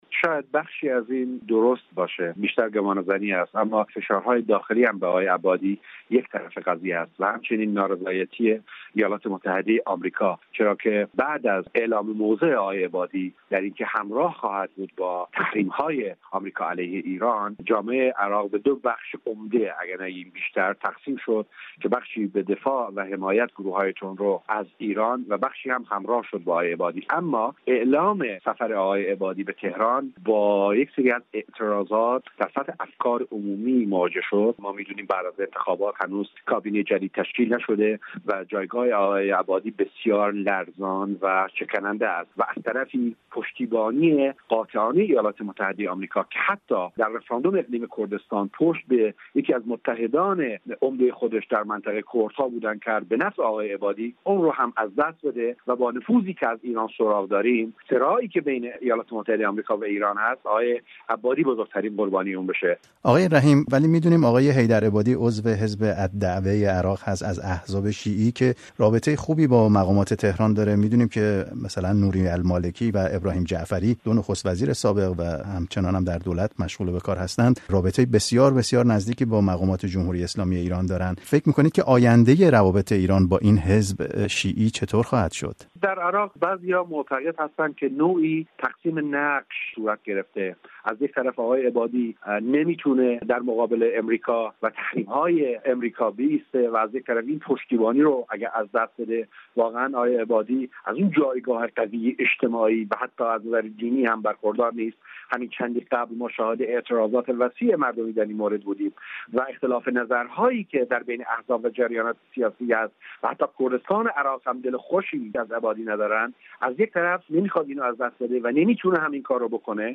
دلایل لغو سفر عبادی به ایران در گفت‌وگو با یک تحلیلگر سیاسی در اربیل عراق